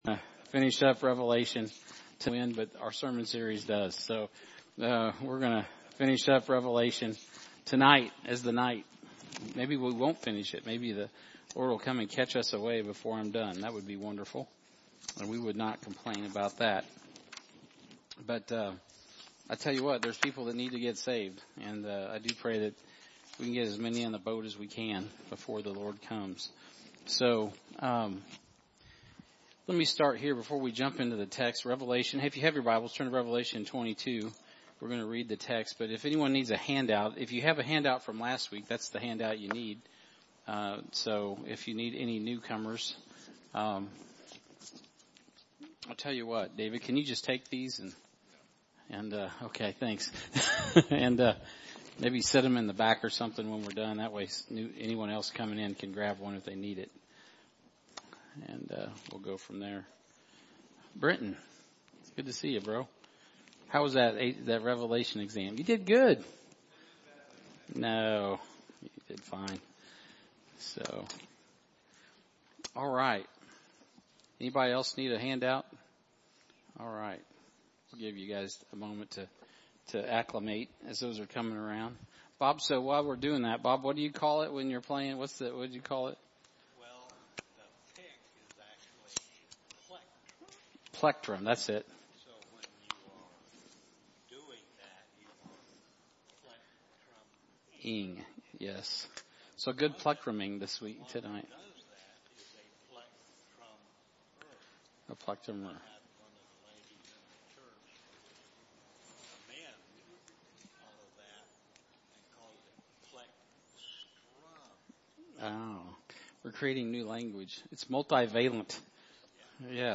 Sermons | Heartland Baptist Fellowship